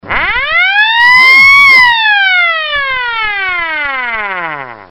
SIRENAS ELECTROMECÁNICAS
112 a 114dB - 1300Hz